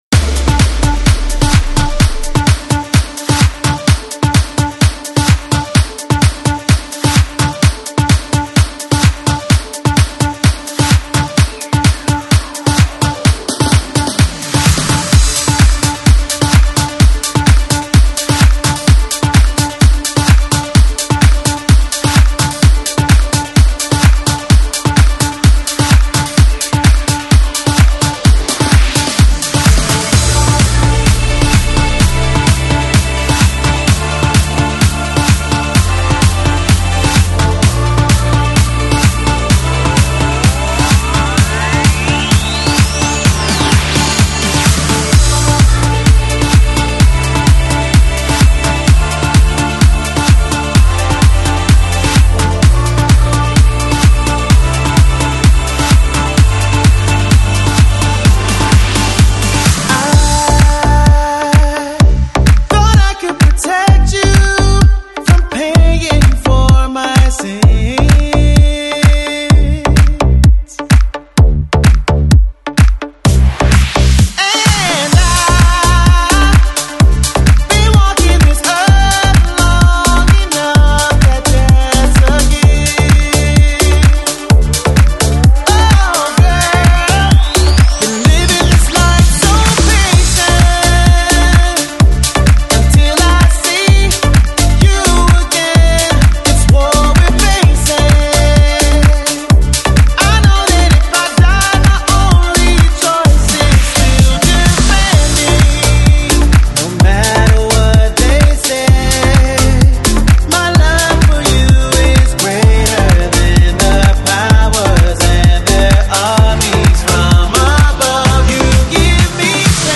FLAC Жанр: House, Dance, Pop Издание
Fitness Version 128 Bpm